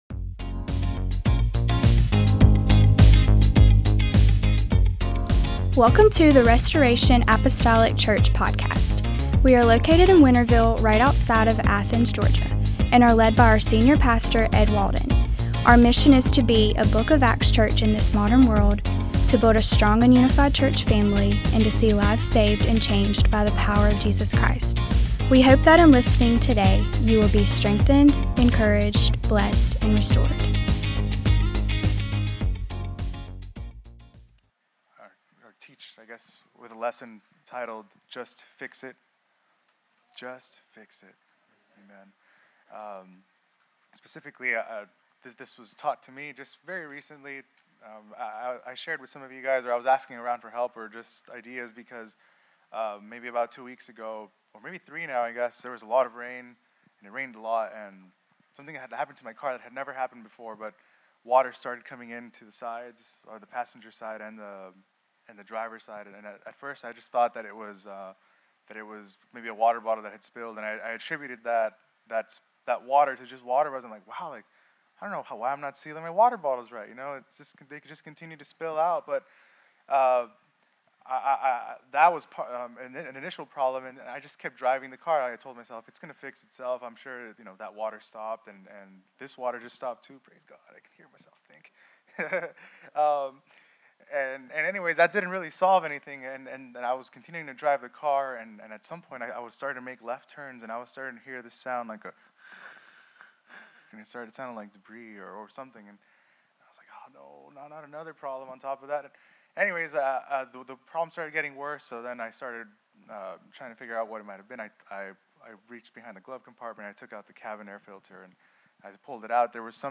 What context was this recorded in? Restoration Apostolic Church Just Fix It Aug 31 2025 | 00:29:53 1x 00:00 / 00:29:53 Subscribe Share Apple Podcasts Spotify Overcast RSS Feed Share Link Embed